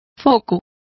Complete with pronunciation of the translation of floodlight.